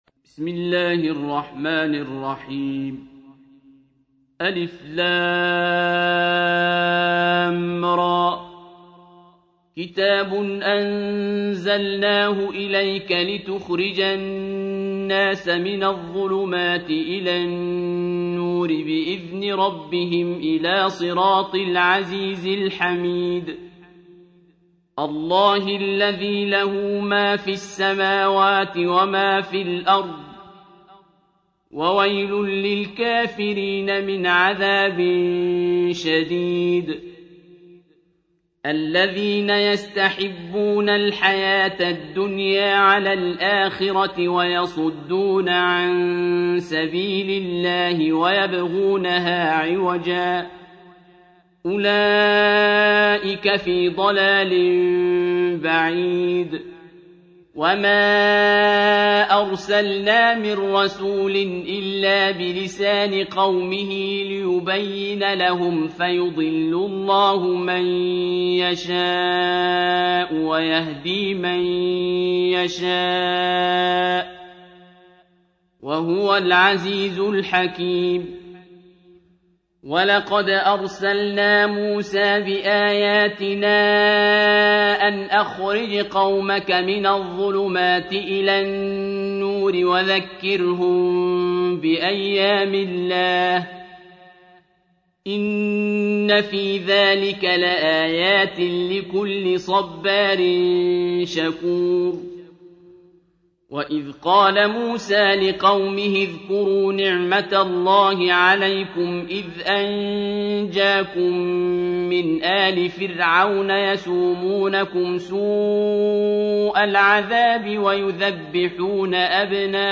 سورة إبراهيم | القارئ عبدالباسط عبدالصمد